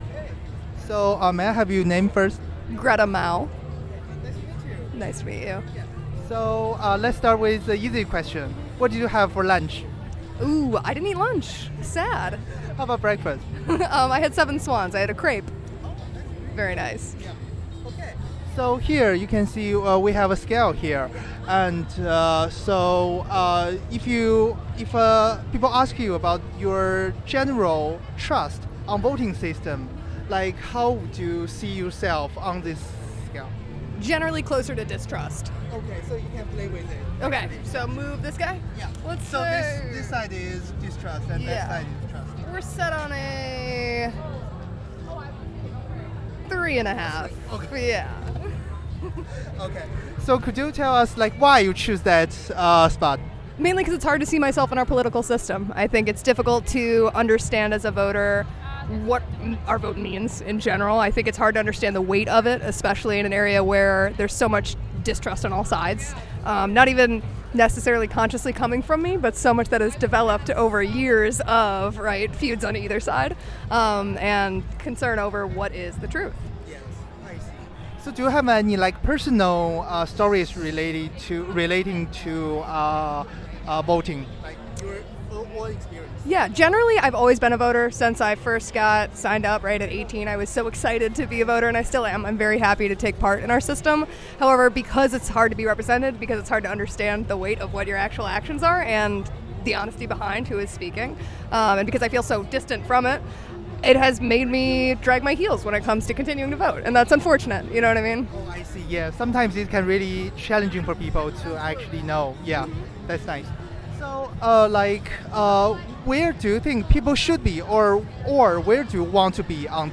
Location MKE Pridefest